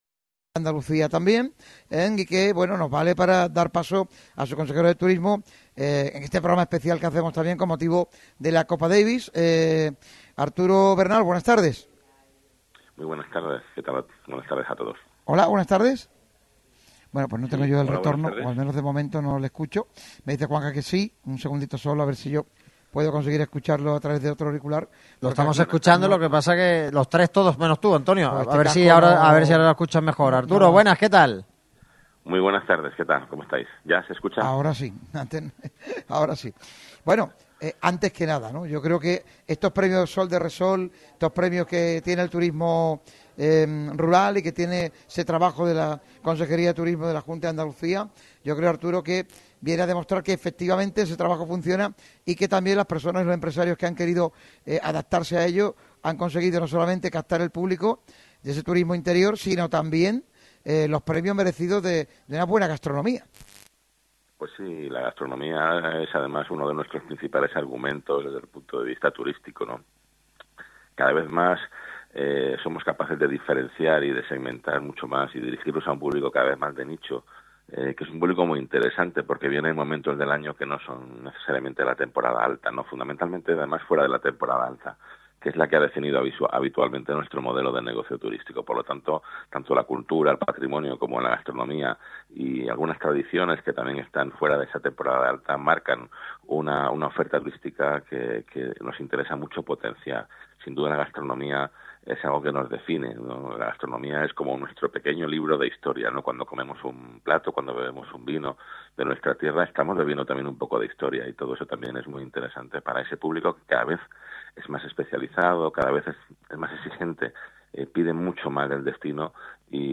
Arturo Bernal, Consejero de Turismo y Andalucía Global de la Junta de Andalucía, ha formado parte del programa de Radio MARCA Málaga que de forma especial se ha realizado desde La Posada del Bandolero desde la localidad de El Borge (Málaga). Ha hablado sobre la posibilidad de que La Nueva Rosaleda esté en una ubicación diferente a la actual con la remodelación de cara al Mundial 2030.